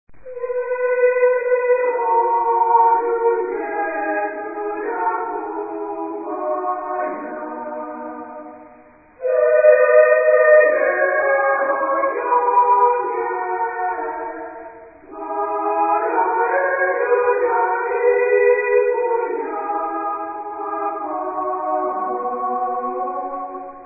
Género/Estilo/Forma: Popular
Tipo de formación coral: SATB  (4 voces Coro mixto )
Tonalidad : si bemol mayor
Origen: Africa